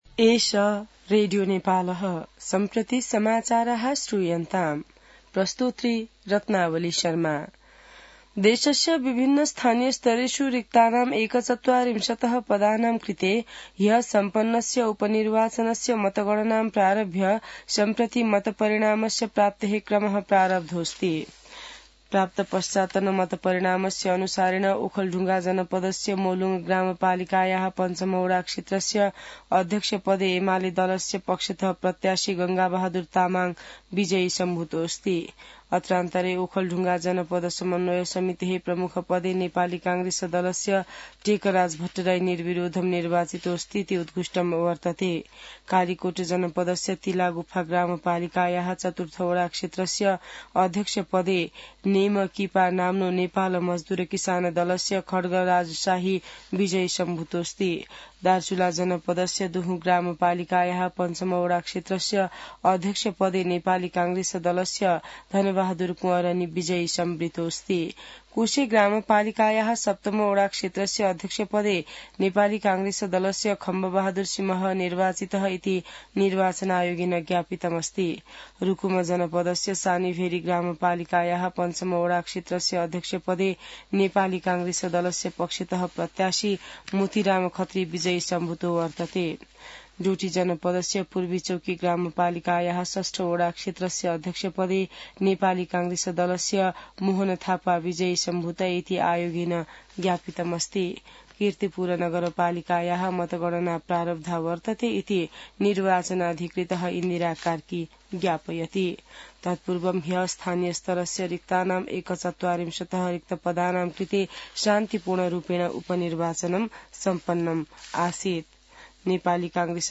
संस्कृत समाचार : १८ मंसिर , २०८१